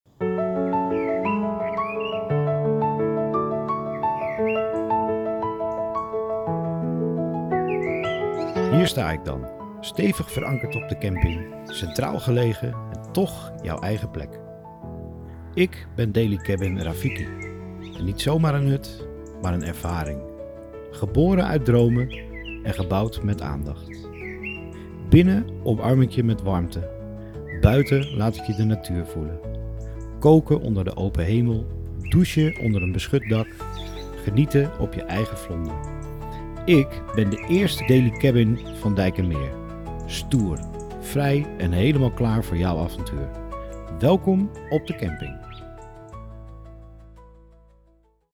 rafiki-compleet-met-muziek.mp3